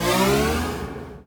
UIMvmt_Slide Power Up Achievement 01.wav